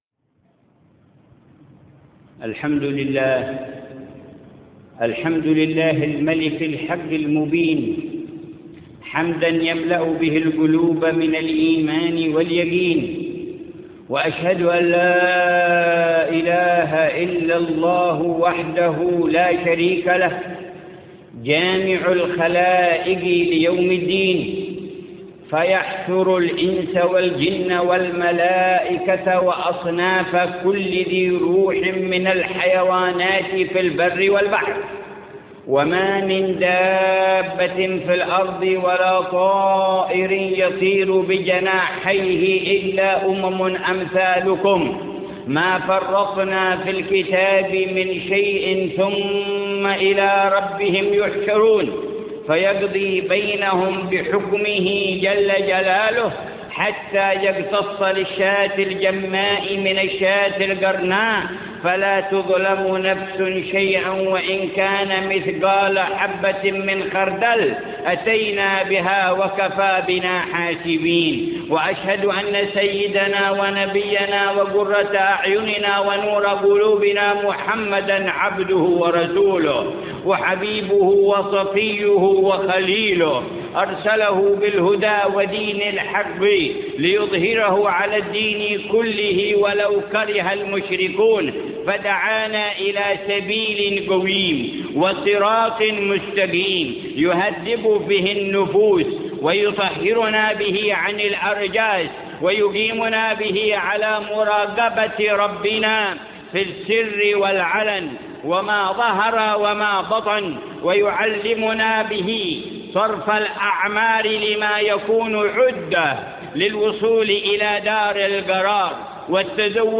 خطبة الجمعة في جامع عمر بالمكلا - حضرموت بتاريخ 1 ربيع الأول 1432هـ.